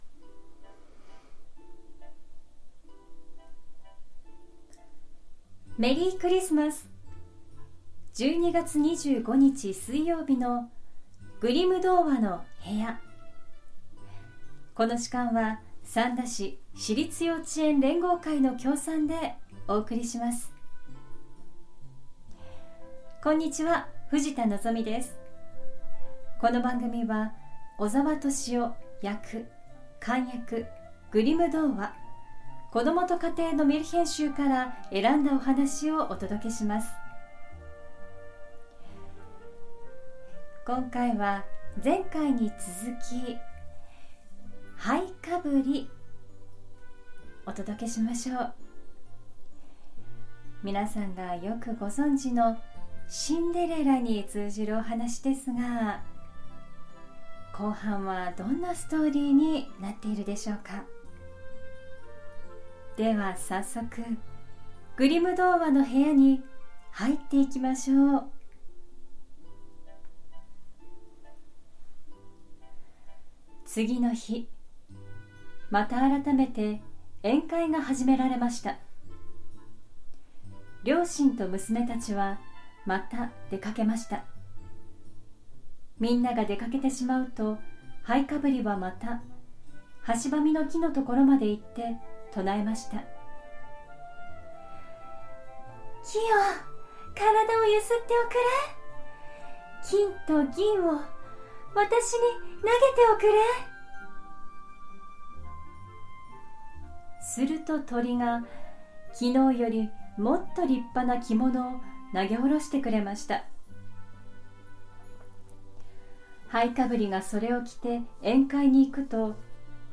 グリム兄弟によって集められたメルヒェン（昔話）を、翻訳そのままに読み聞かせします📖 今回お届けするのは、11月に続いて『灰かぶり』。 「シンデレラ」としてよく知られるお話です✨ 魔法のおばあさんや動物たちに助けられてお城の舞踏会に参加し、ガラスの靴を残して…というのは映画や絵本で定番のストーリーですが、実際のところは異なる展開をしていました📖 どんなお話の続きが待っているのでしょう… 12月配信では後半をお届けしましょう♪昔話の本当のストーリーを、お子さんも大人の方もどうぞお楽しみください🌷